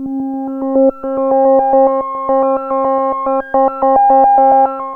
JUP 8 C5 11.wav